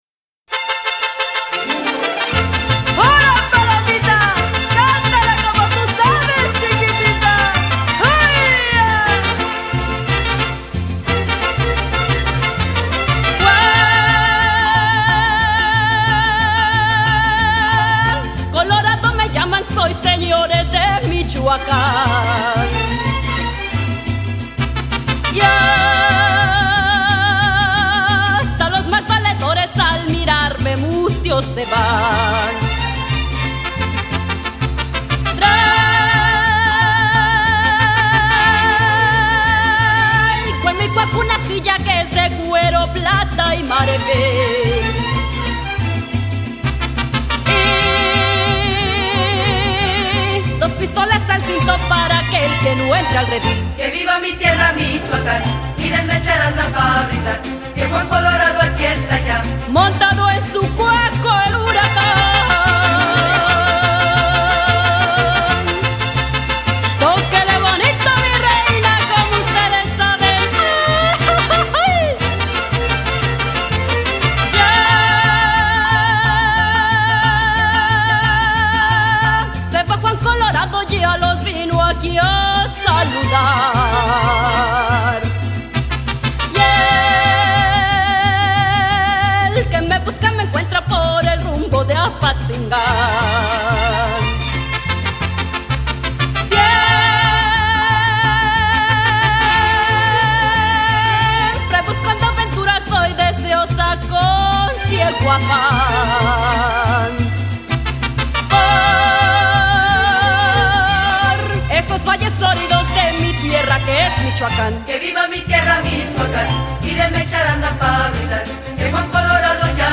sensitivity, beauty, warmth and vivacious spirit